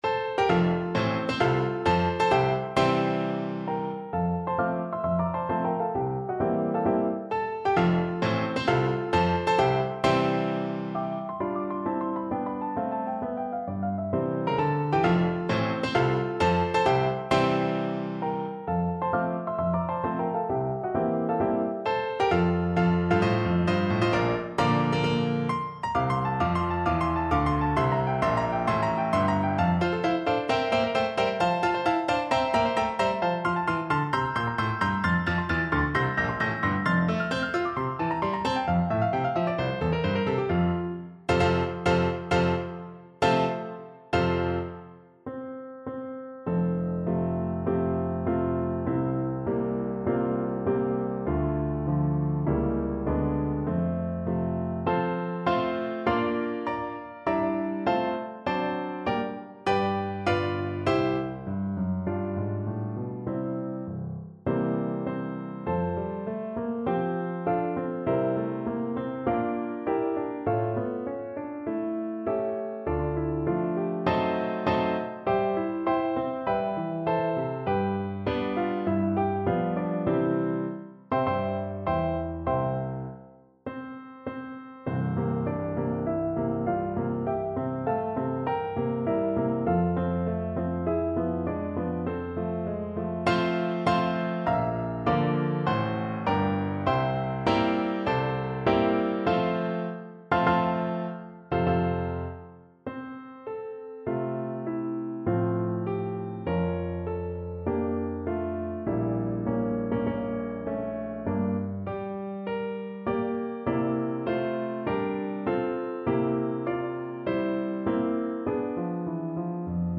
Energico (=c.144) =132
4/4 (View more 4/4 Music)
C5-F6